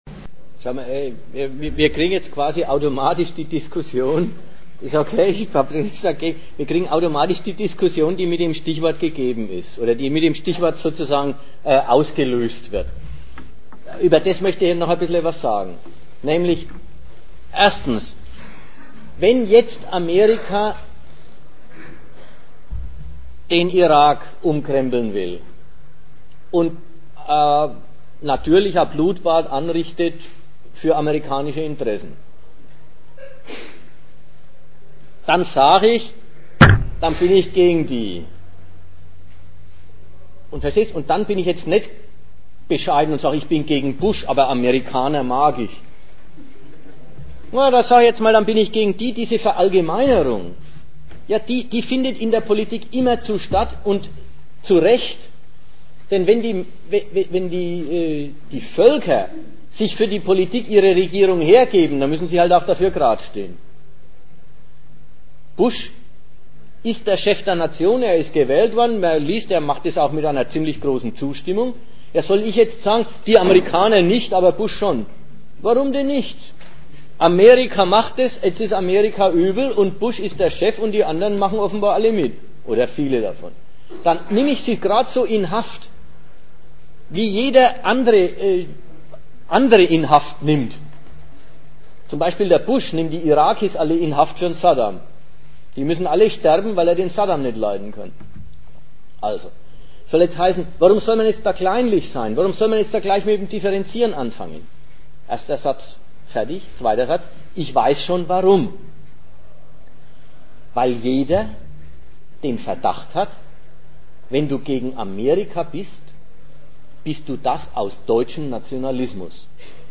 Datum 13.03.2003 Ort Nürnberg Themenbereich Staatenkonkurrenz und Imperialismus Veranstalter unbekannt Dozent Gastreferenten der Zeitschrift GegenStandpunkt Wenn die Träger der Macht Krieg ansagen, dann wächst der Wunsch der Menschen nach Frieden.